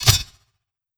Whoosh Blade 005.wav